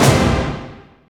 Index of /90_sSampleCDs/Optical Media International - Sonic Images Library/SI2_SI FX Vol 3/SI2_Gated FX 3